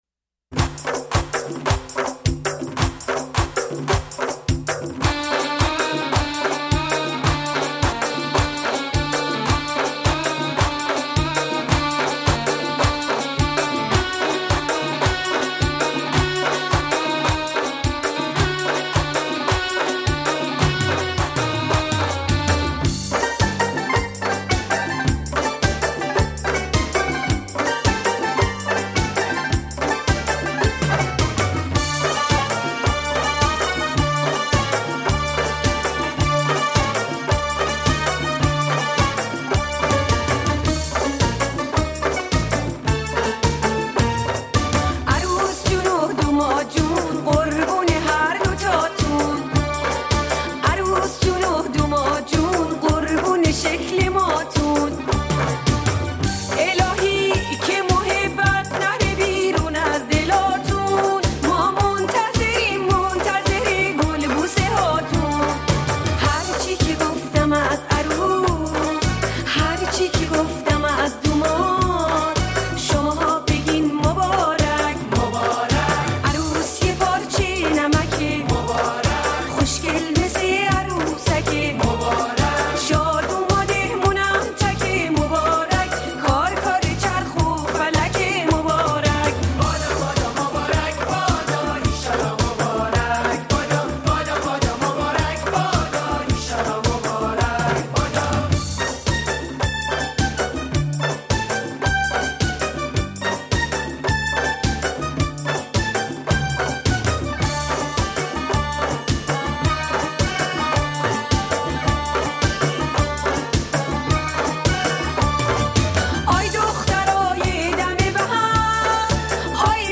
آهنگ ایرانی رقص عروس داماد